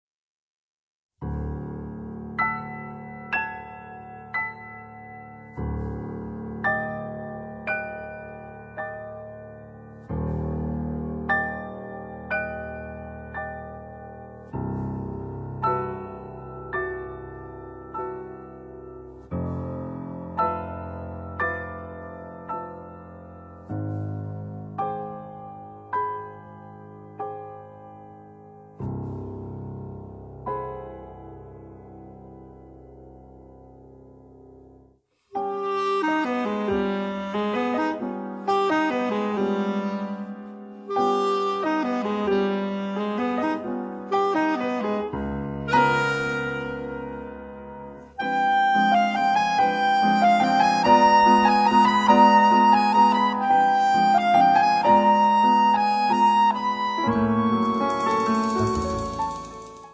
piano
sax tenore e soprano, clarinetto
contrabbasso
batteria